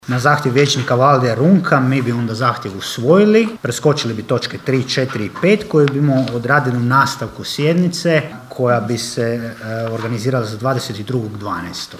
Sjednica Općina Kršan
Nakon pauze, koju su zatražili vladajući, predsjednik Vijeća Ivan Zambon je ustvrdio: (